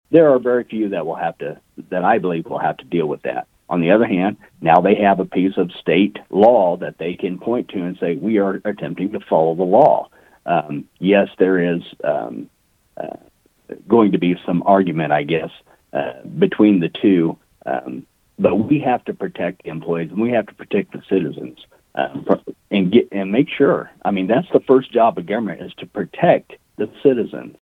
76th District Representative Eric Smith of Burlington also voted for the bill. He doesn’t expect the level of business impact that others see as likely.